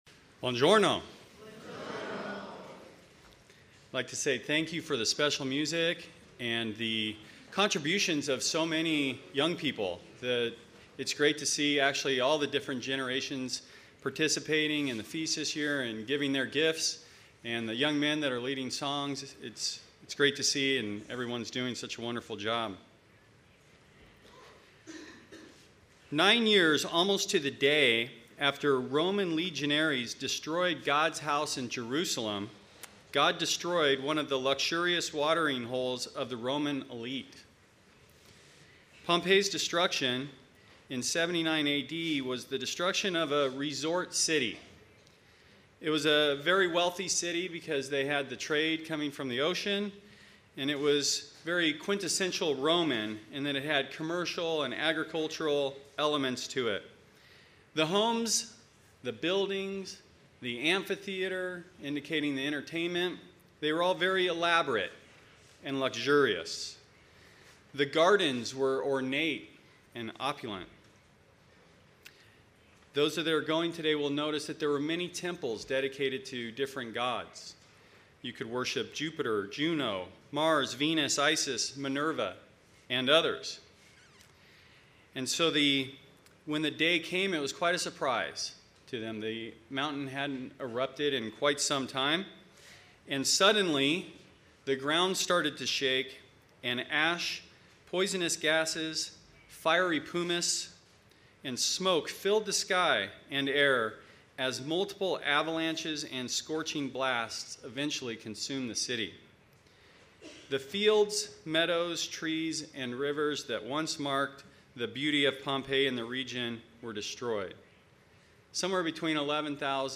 Sermon
FOT 4th day – Italy 2017